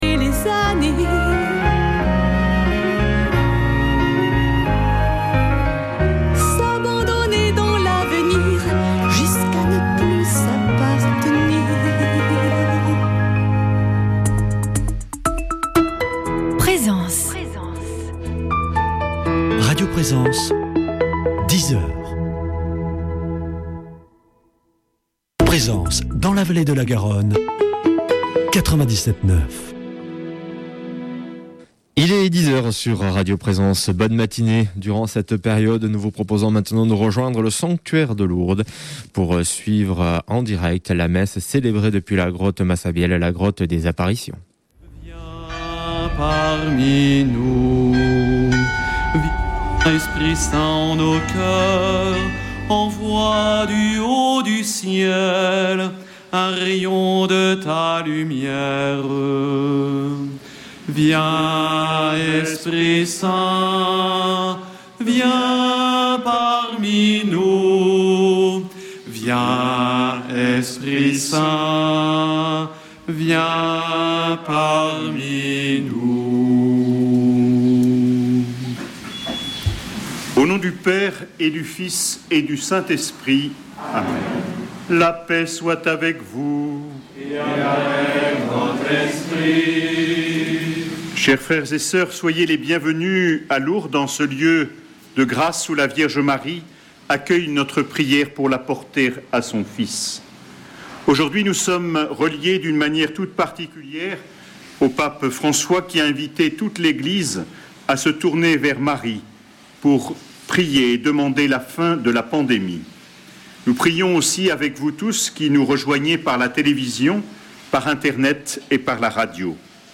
Messe depuis le sanctuaire de Lourdes du 18 mai